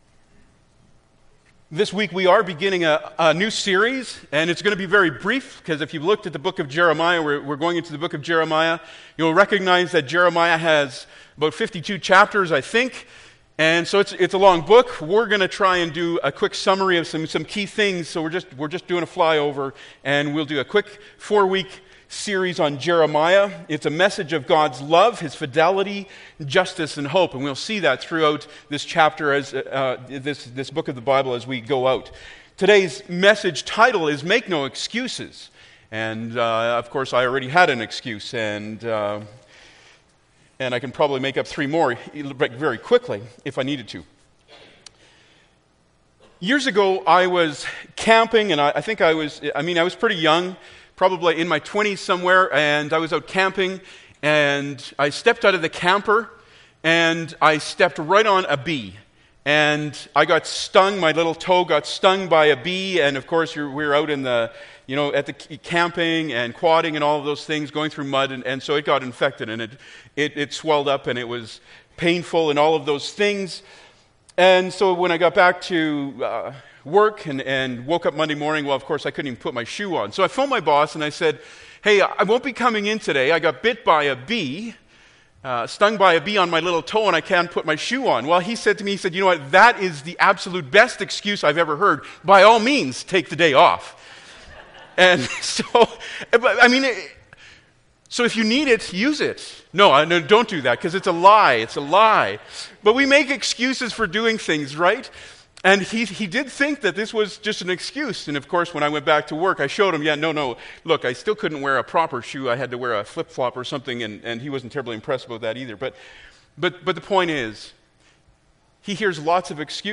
Jeremiah 1:1-10 Service Type: Sunday Morning Bible Text